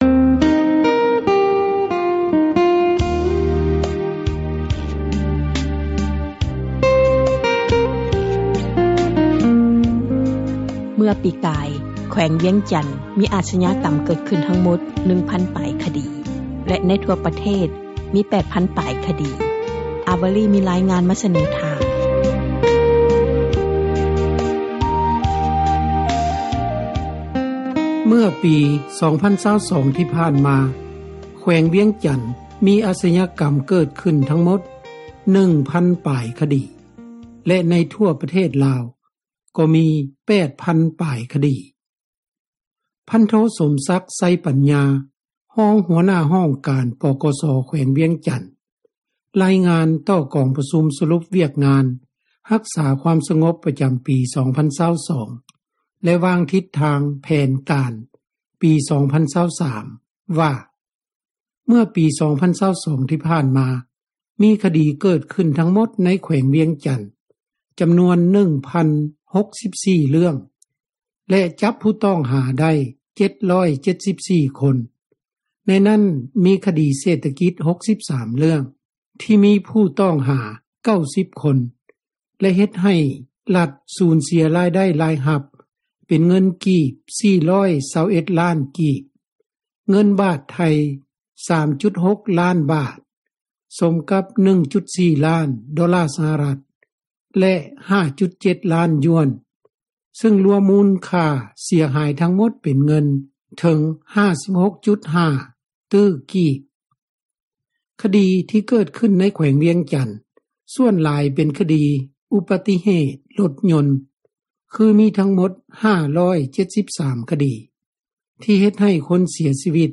ຊາວແຂວງວຽງຈັນ ນາງນຶ່ງ ໃຫ້ຄຳຄິດຄຳເຫັນ ກ່ຽວກັບບົດຣາຍງານ ການກໍ່ອາຊຍາກັມ ໃນແຂວງວຽງຈັນນັ້ນວ່າ ນາງຢາກຮຽກຮ້ອງ ໃຫ້ຕຳຣວດແຂວງວຽງຈັນ ປັບປຸງການດຳເນີນຄະດີ ໃຫ້ໄວຂຶ້ນ.